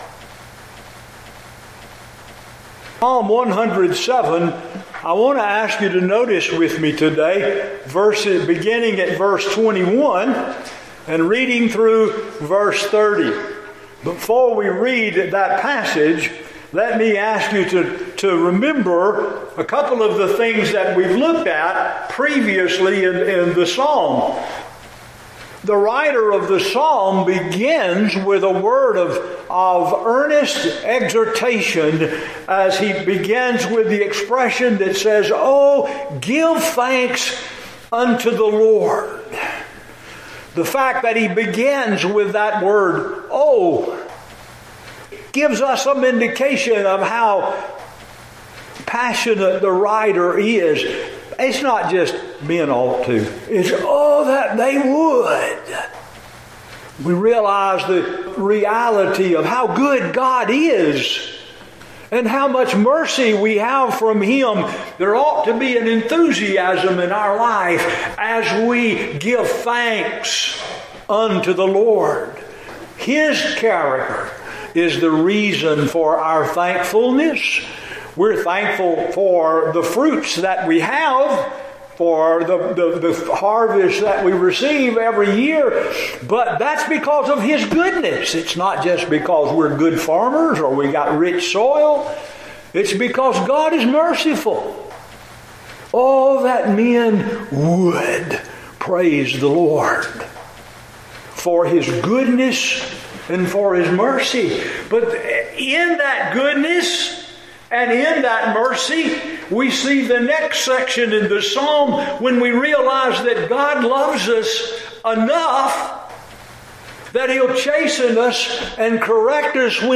Psalms 100, Thanksgiving Nov 28 In: Sermon by Speaker